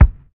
LO FI 1 BD.wav